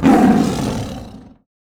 CosmicRageSounds / wav / general / combat / creatures / tiger / he / turn1.wav